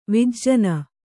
♪ vijjana